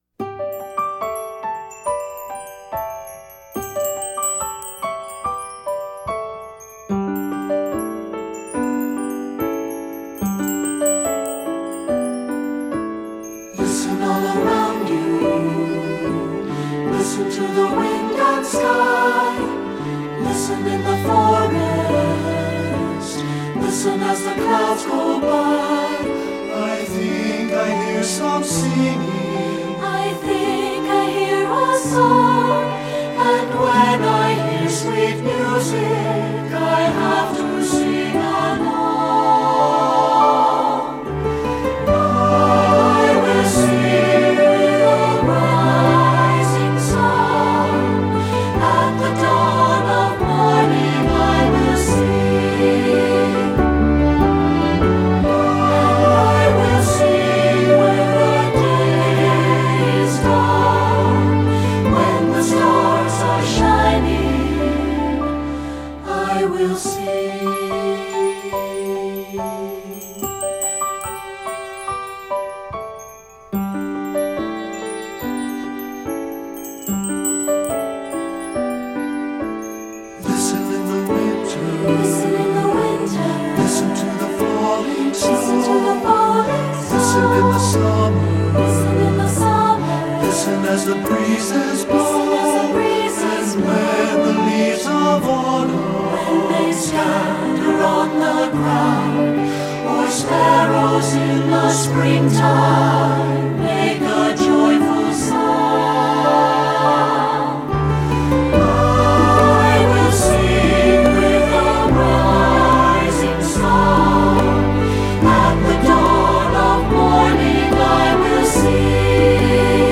Voicing: 3-Part and Piano